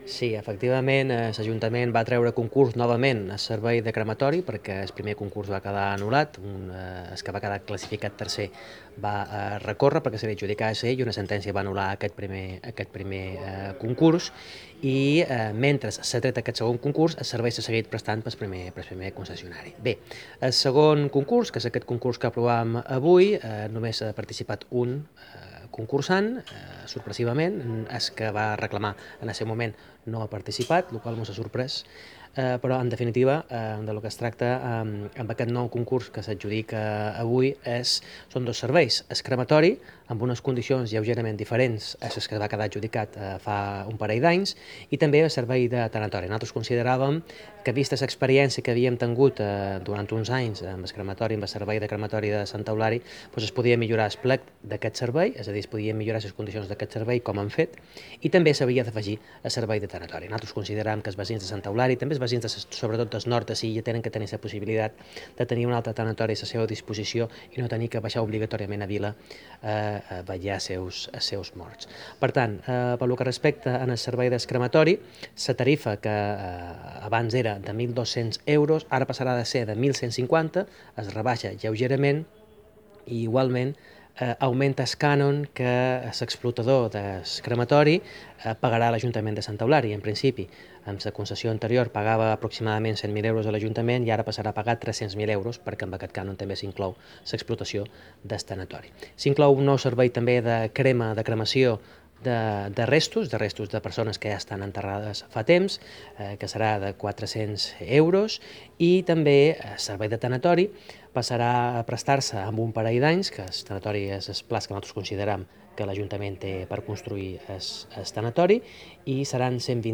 Tanatorio y crematorio - corte de voz Mariano Juan